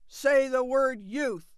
OAF_youth_angry.wav